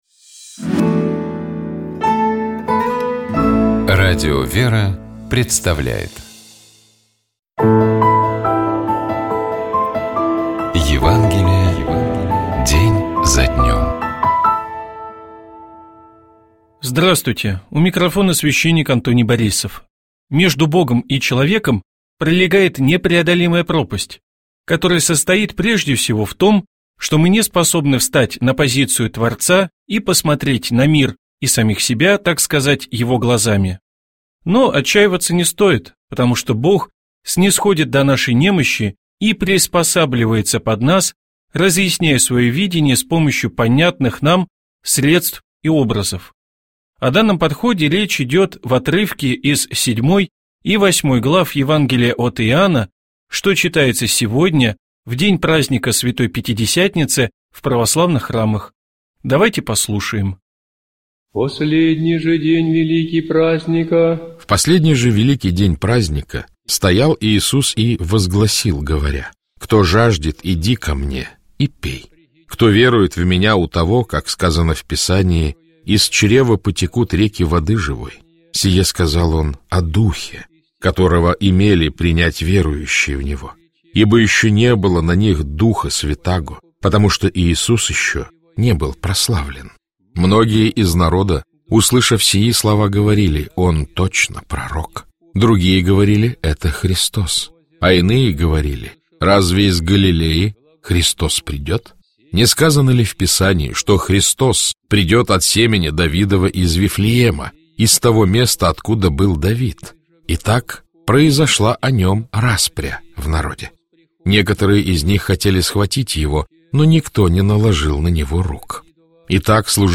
Читает и комментирует